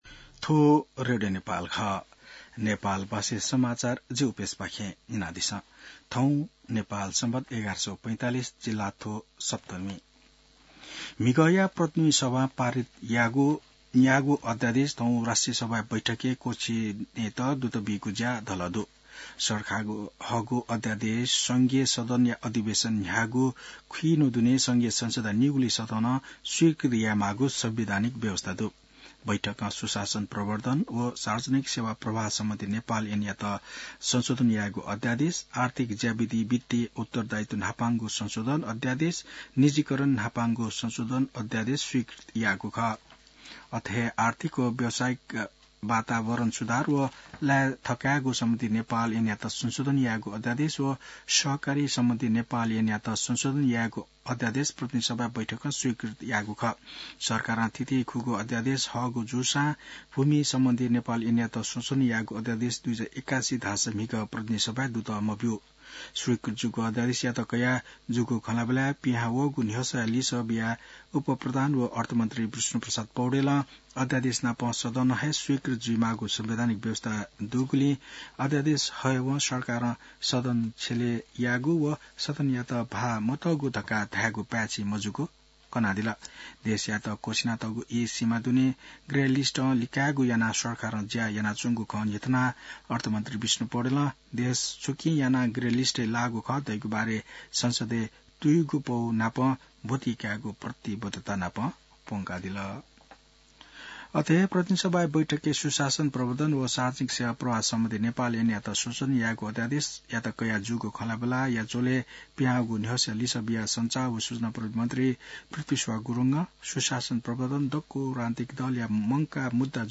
नेपाल भाषामा समाचार : २३ फागुन , २०८१